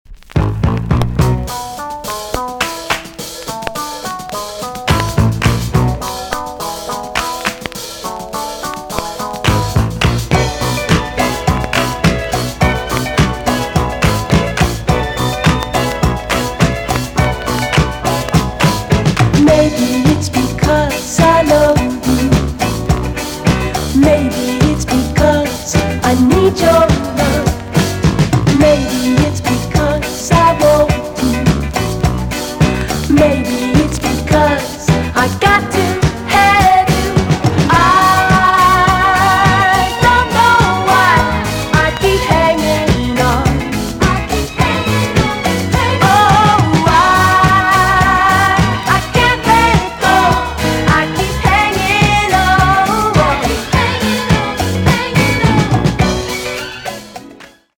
EX-~VG+ 少し軽いチリノイズが入る箇所があります。
1976 , WICKED JAMAICAN SOUL TUNE!!